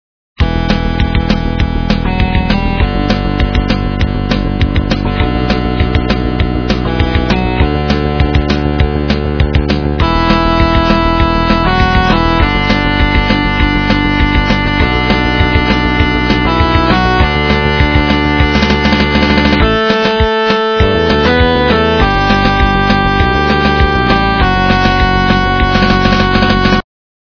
качество понижено и присутствуют гудки
полифоническую мелодию